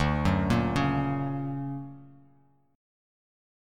D+M7 chord